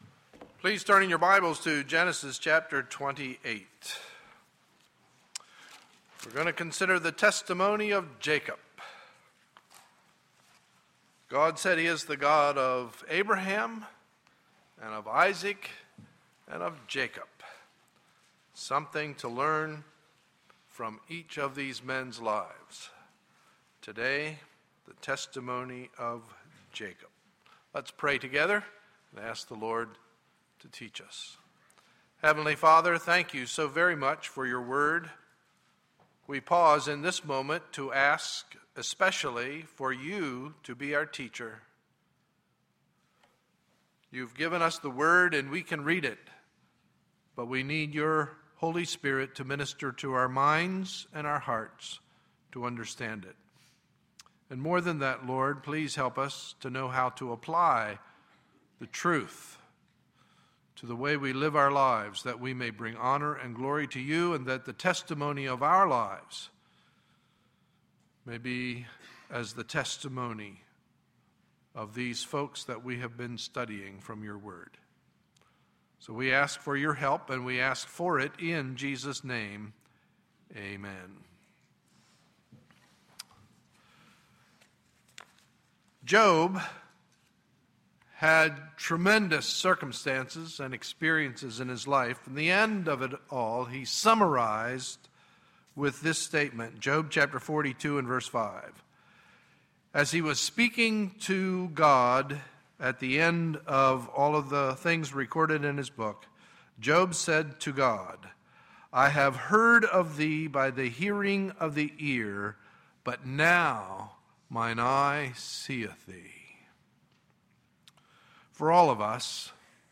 Sunday, April 22, 2012 – Morning Message